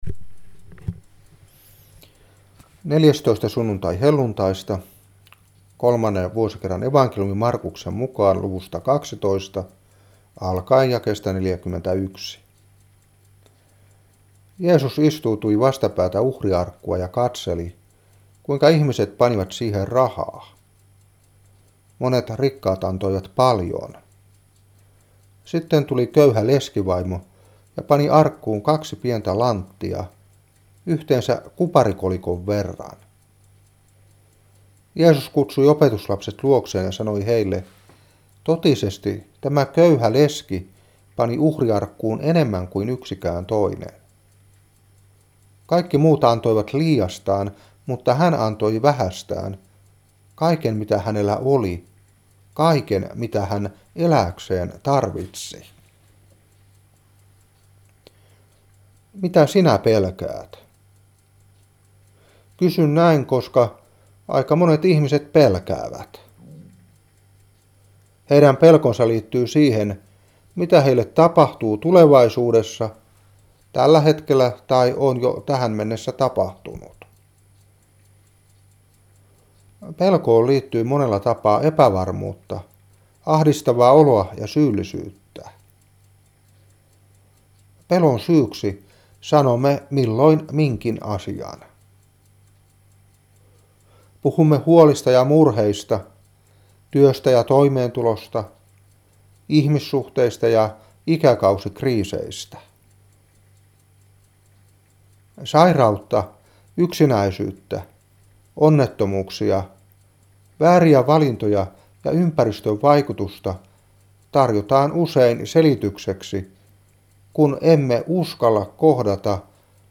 Saarna 2012-9.